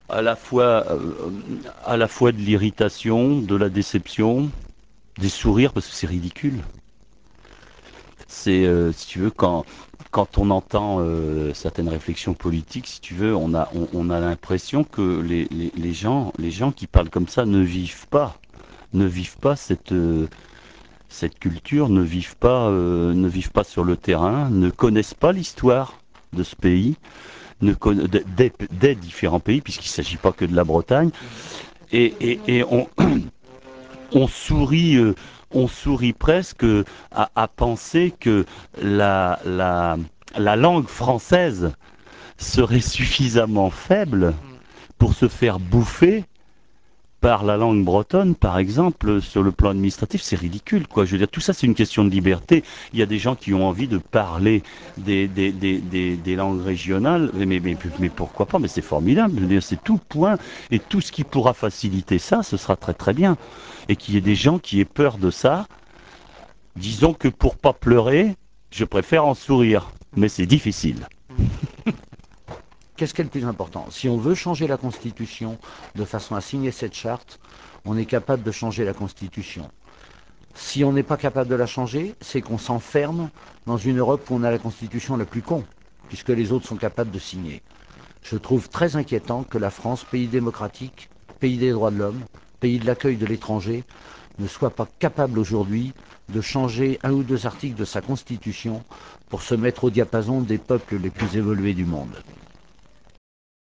L'INTERVIEW